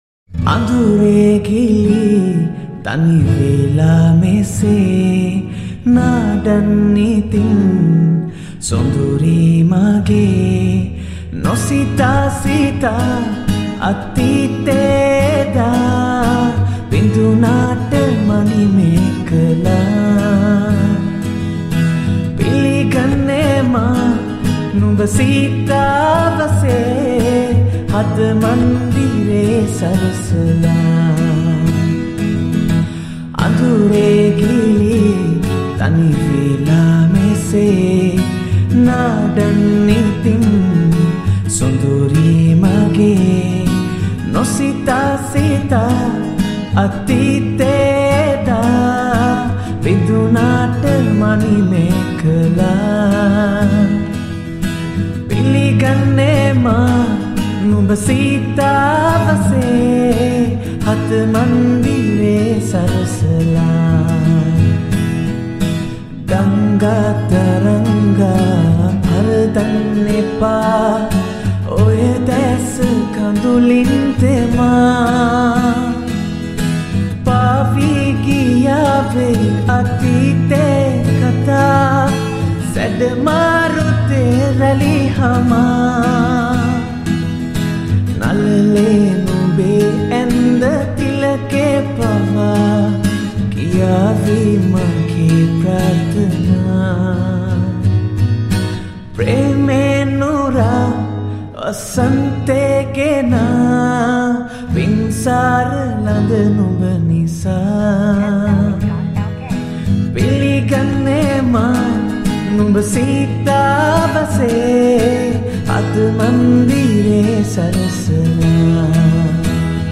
Acoustic Guitar Cover
Cover Version